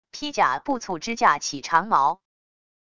披甲步卒支架起长矛wav音频